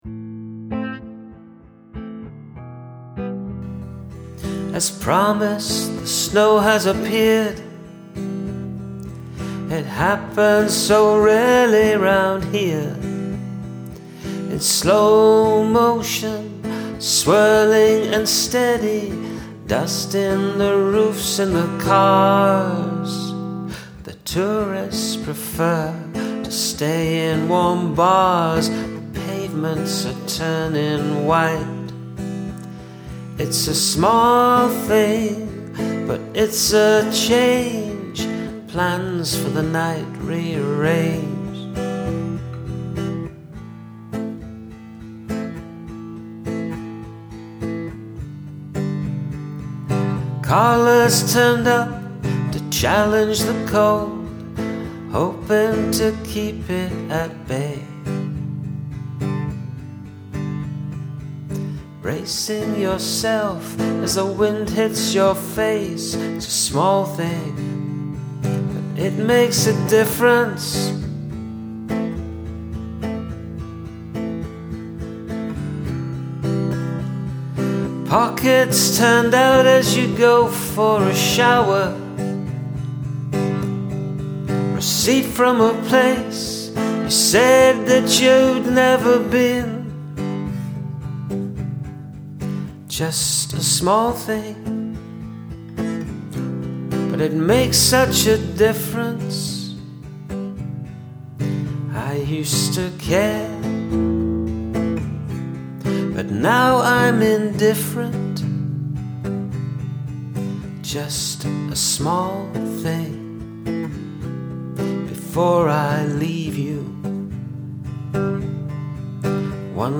Beautiful singing and playing ✨
Lovely sounding tune. Guitar & vocals work well together.